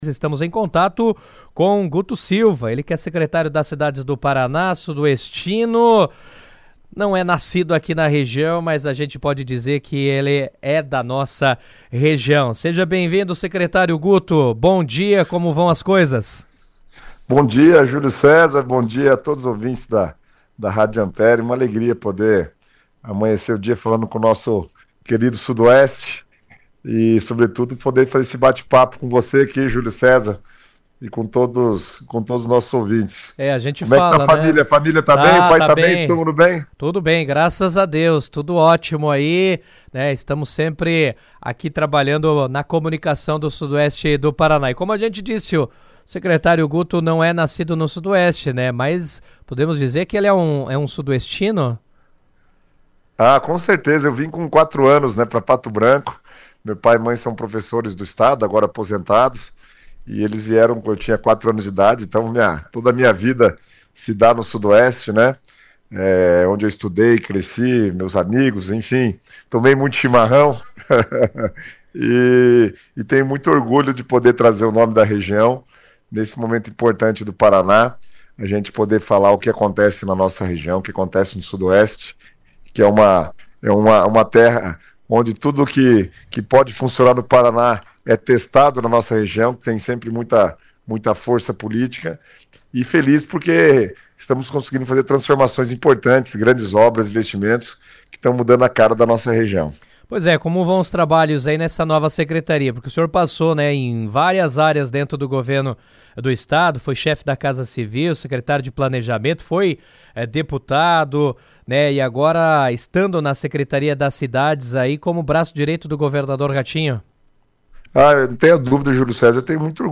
O secretário das Cidades do Paraná, Guto Silva, concedeu entrevista ao Jornal RA 1ª Edição, da Ampére AM nesta quinta-feira, 18. Ele destacou os investimentos em todas as regiões do Estado, a redução da alíquota do IPVA, tratou sobre eleições de 2026 e anunciou a liberação de novos recursos para o Sudoeste.